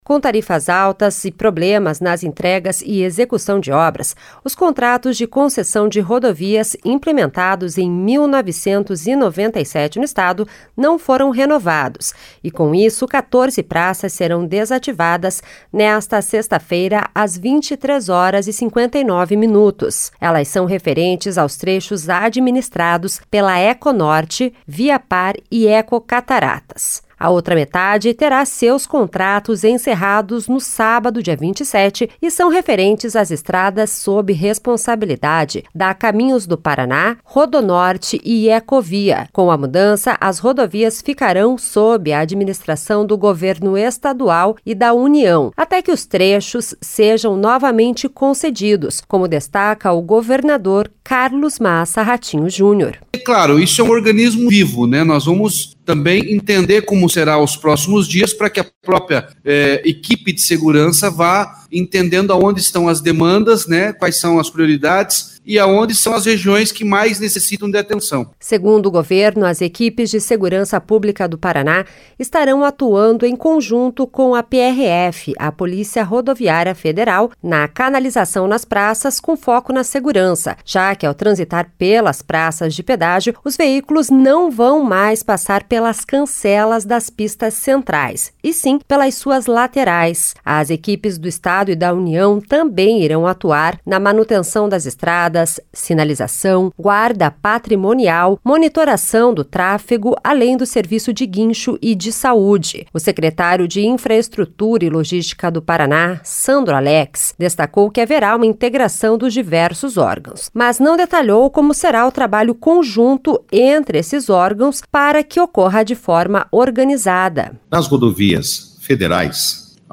Em coletiva, governador ressalta que está trabalhando de forma integrada com o Governo Federal para garantir a segurança dos cidadãos e os atendimentos nas rodovias que serão liberadas do pedágio a partir deste final de semana